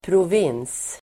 Ladda ner uttalet
Uttal: [prov'in:s]